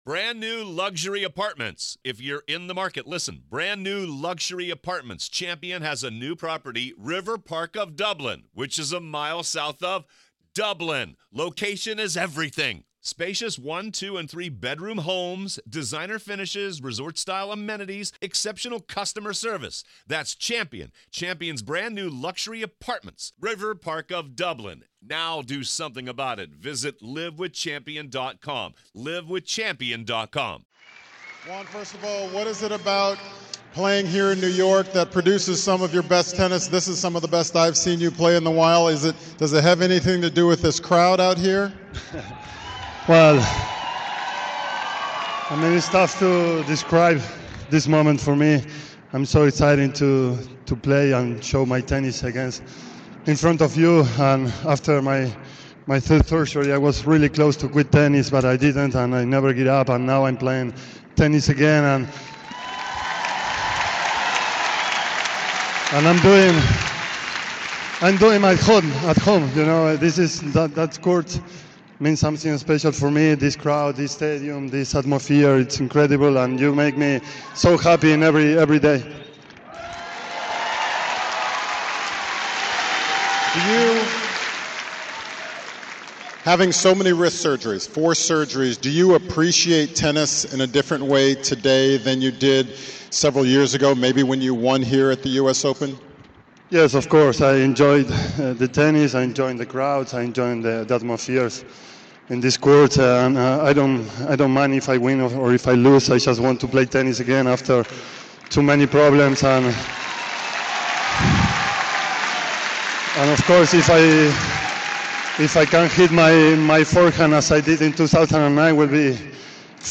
In his post match interview, he explains why he loves playing here. del Potro isn’t sure if he is at his best, but he’s happy to be winning.